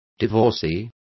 Complete with pronunciation of the translation of divorcee.